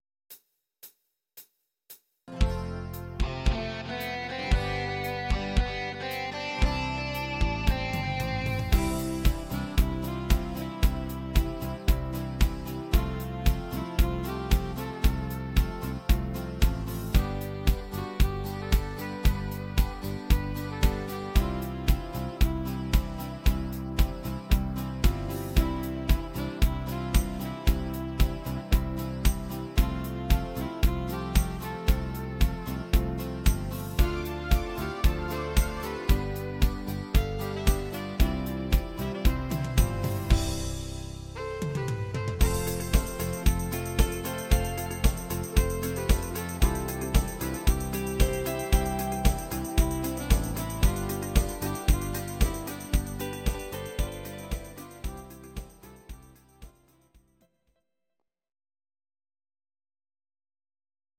Instumental Sax